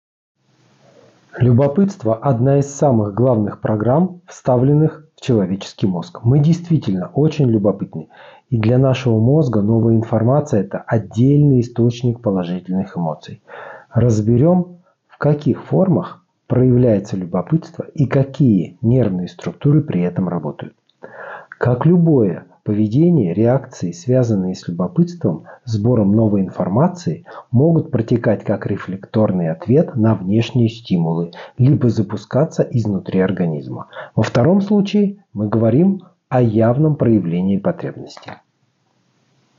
Проба голоса.m4a
Мужской